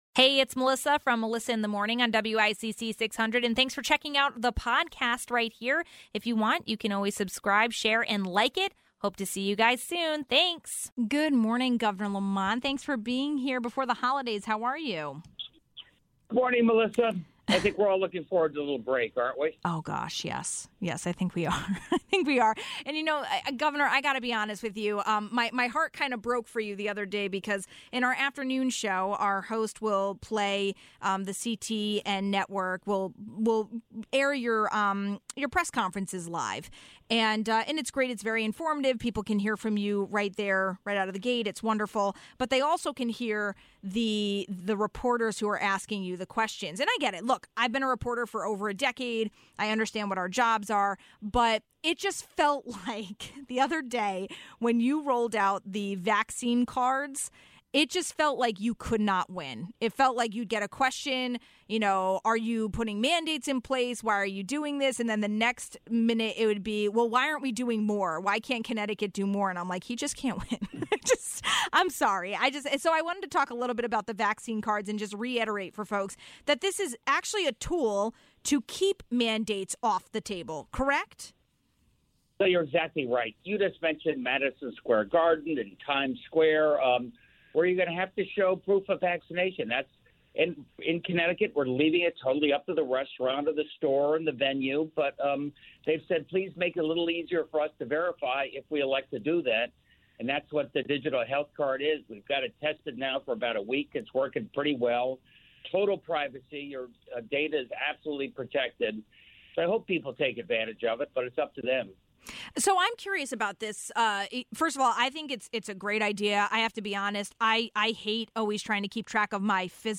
1. Governor Lamont talked about the covid vaccine QR code card, social media threats and climate change initiatives.